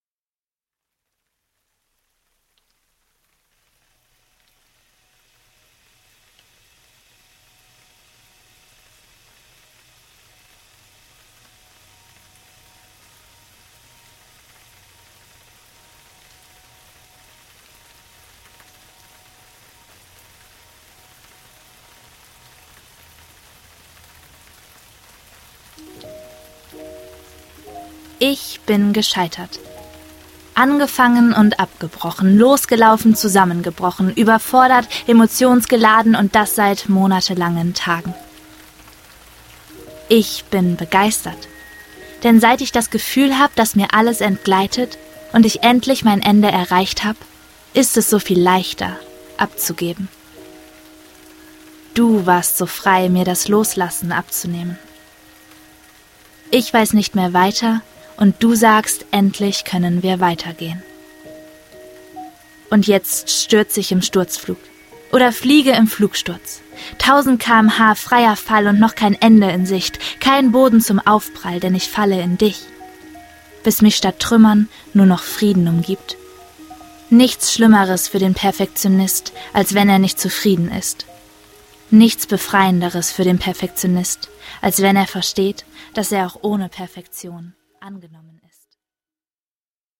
Im Ascheregen (MP3-Hörbuch - Download)